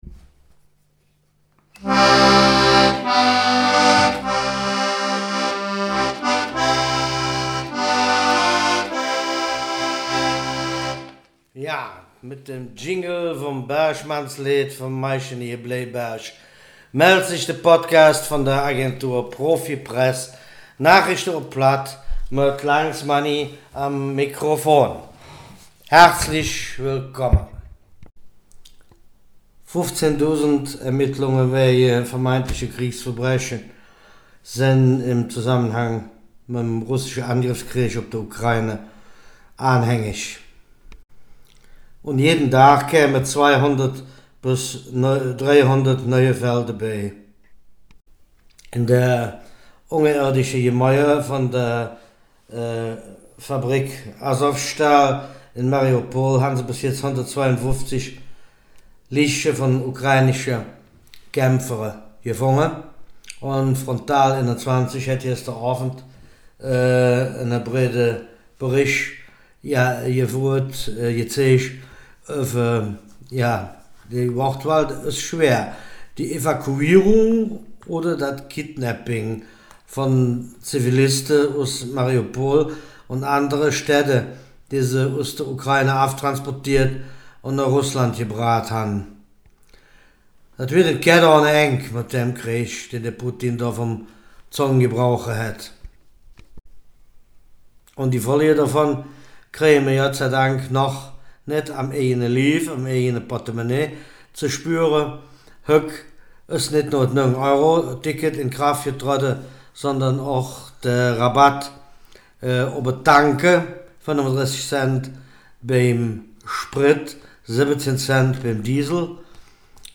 Nachrichten vom 1. Juni
Podcast op Platt Nachrichten vom 1.